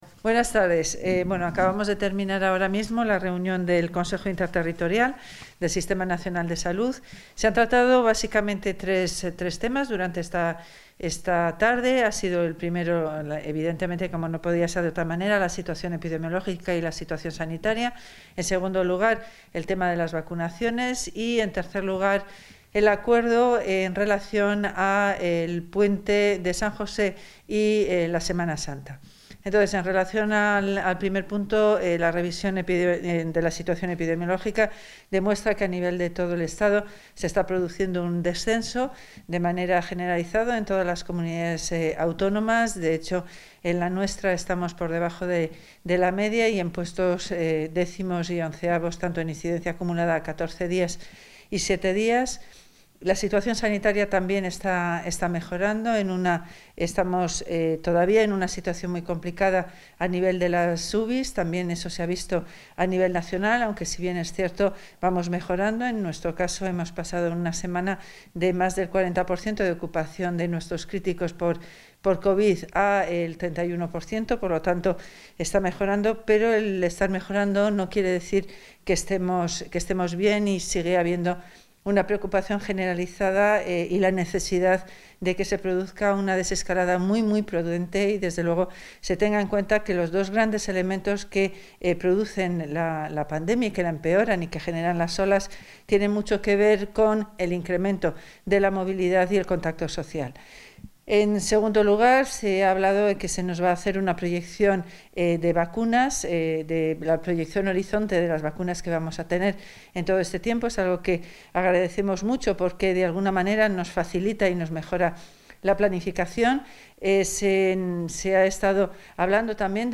Valoración de la consejera.